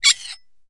玻璃 陶瓷 " 陶瓷冰淇淋碗金属勺子在碗内发出刺耳的声音 01
描述：用金属勺刮擦陶瓷冰淇淋碗的内部。 用Tascam DR40录制。
Tag: 刮下 金属勺 尖叫 刮去 勺子 尖叫 陶瓷 金属